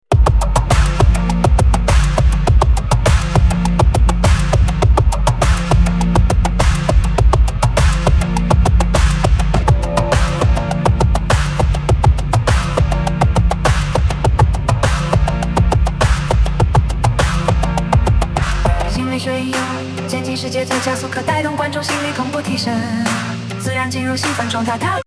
从原速的 80% 开始，每 30 秒加速 5%，至副歌时达到原速 110%
渐进式节奏加速可带动观众心率同步提升，自然进入兴奋状态